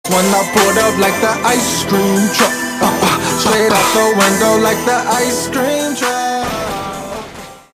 icecreamtruck.mp3